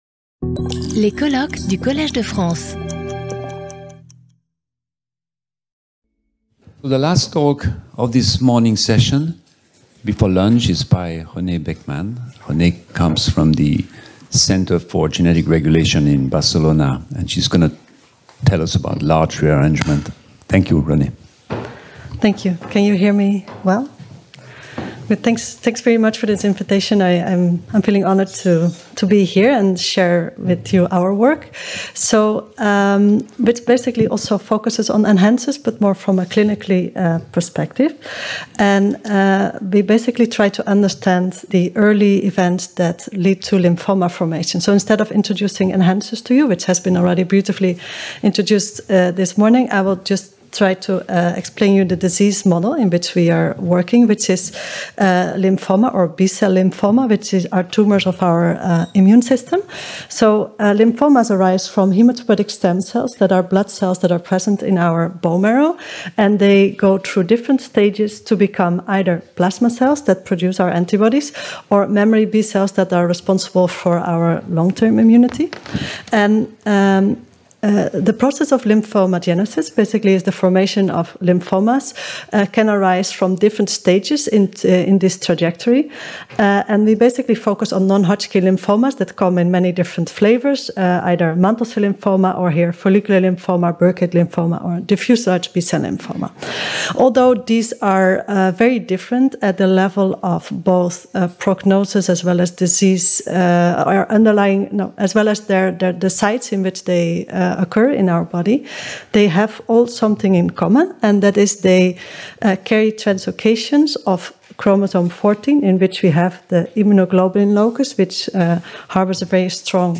Lecture audio